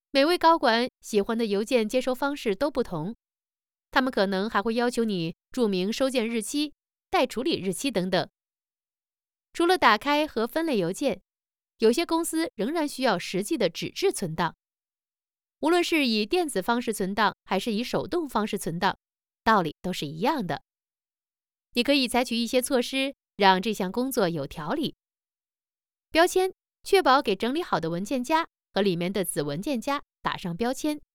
Chinese_Female_044VoiceArtist_2Hours_High_Quality_Voice_Dataset
Text-to-Speech